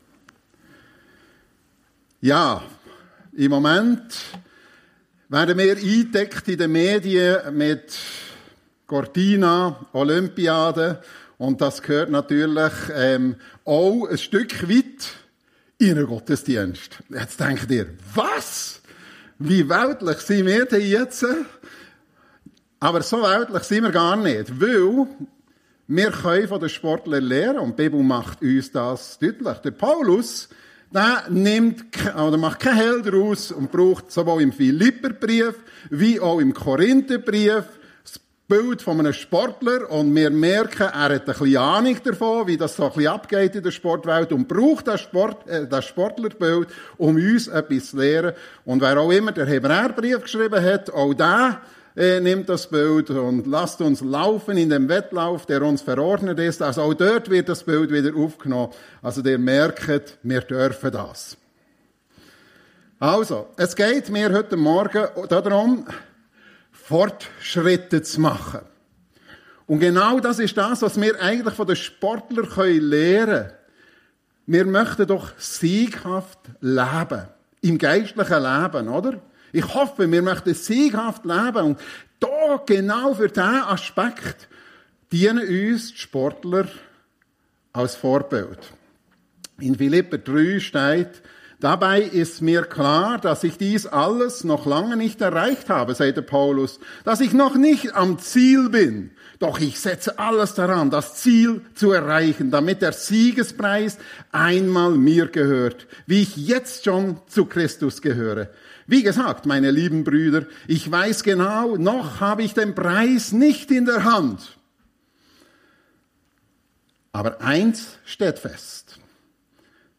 Sieghaft Leben - Fortschritte machen ~ FEG Sumiswald - Predigten Podcast